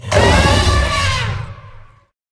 CosmicRageSounds / ogg / general / combat / enemy / baurg / att2.ogg